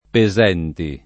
[ pe @$ nti ]